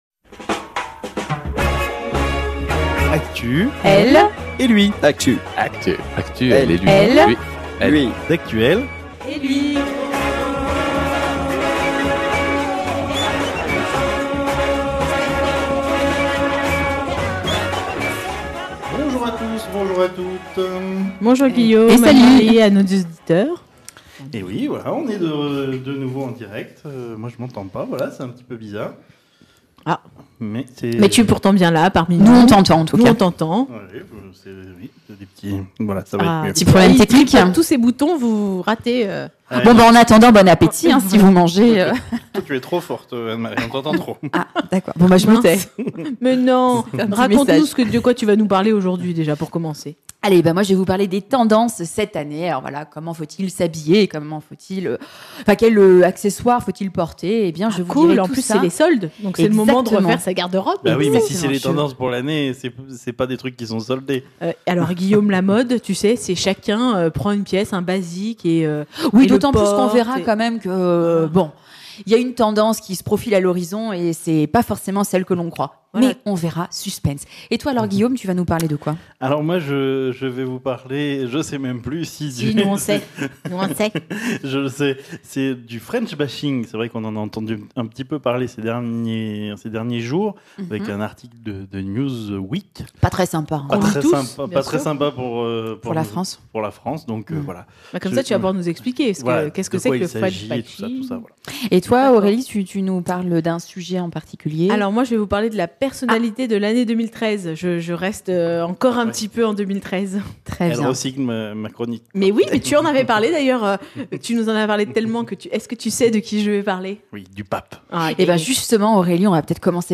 Emission en direct le jeudi 16 janvier à 12h30 et rediffusée le mardi 21 janvier à 18h11, le jeudi 23 janvier à 12h30, et le mardi 28 janvier à 18h11.